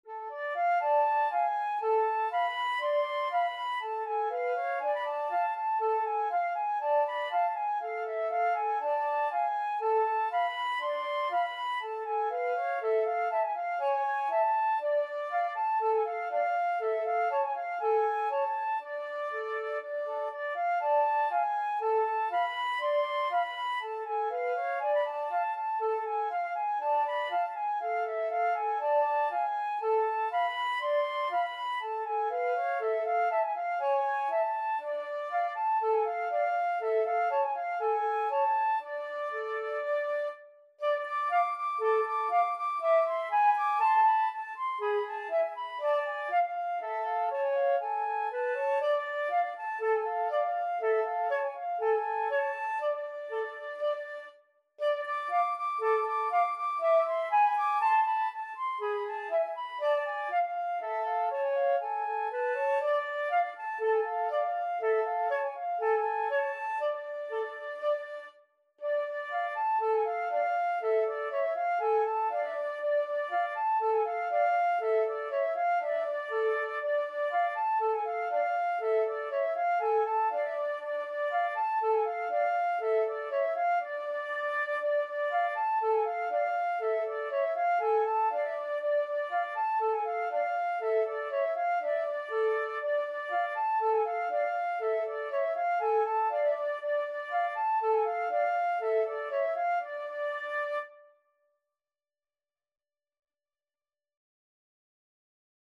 Free Sheet music for Flute Duet
Flute 1Flute 2
Odessa Bulgarish is a traditional klezmer piece originating in the Ukraine.
4/4 (View more 4/4 Music)
C major (Sounding Pitch) (View more C major Music for Flute Duet )